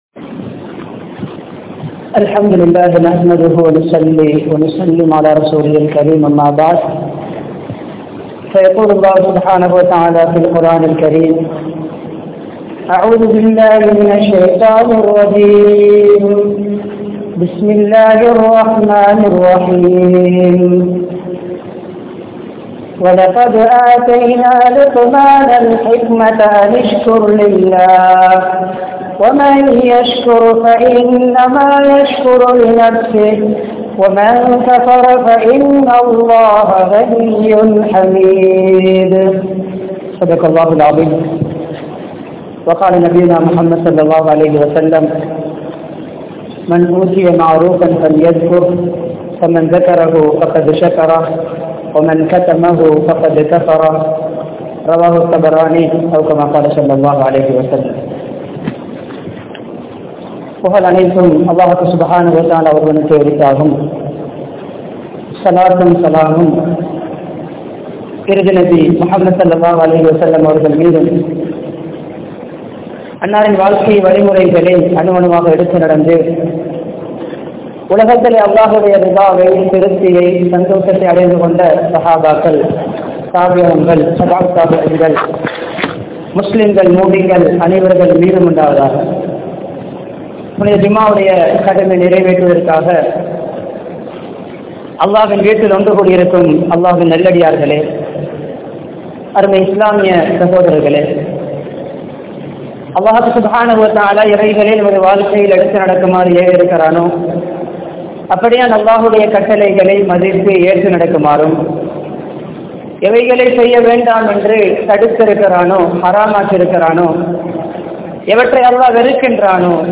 Manitharhalukku Nantri Ullavaraah Irungal (மனிதர்களுக்கு நன்றி உள்ளவராக இருங்கள்) | Audio Bayans | All Ceylon Muslim Youth Community | Addalaichenai
Panadura, Pallimulla Jumua Masjith